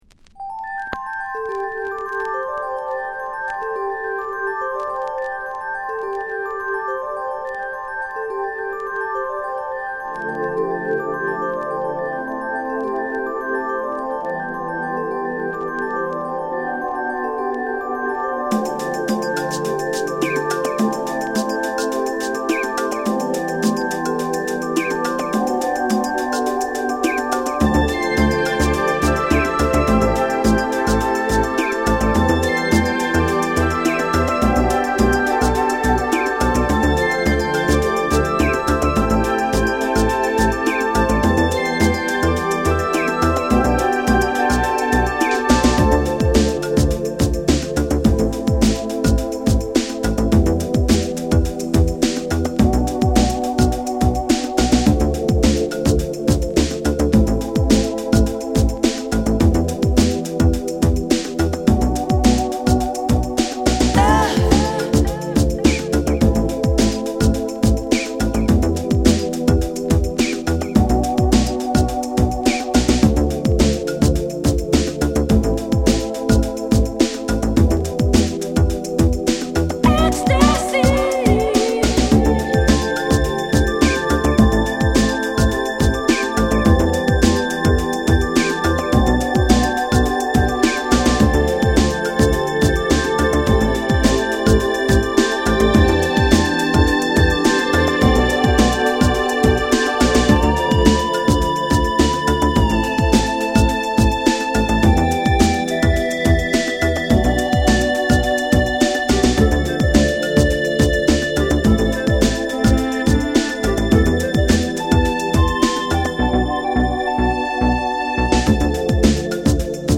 グラウンドビートチルアウト